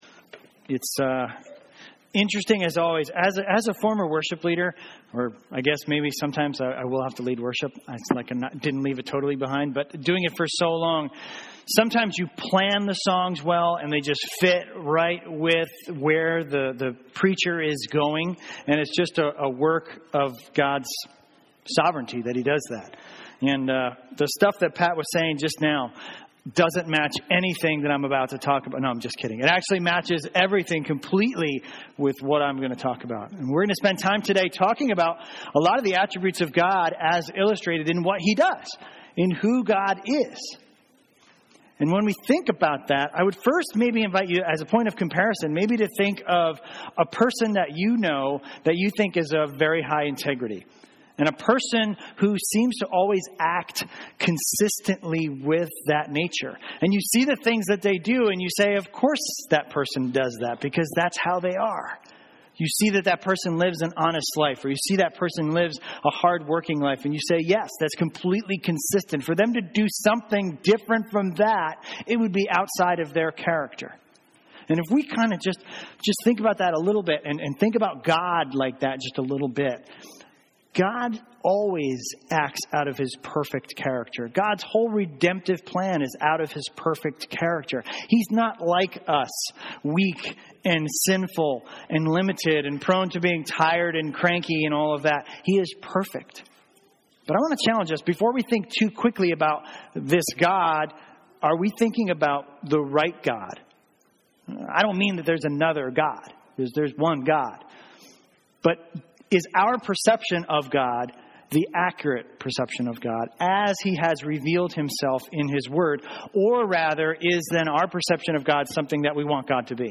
A message from the series "Revelation." In Revelation 5:1-14 we learn that only Jesus is worthy to be the center of God's plan.